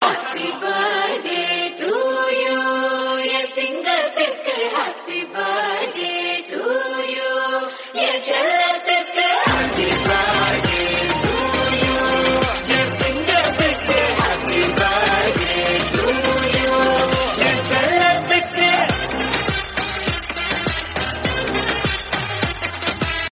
a lively and fun track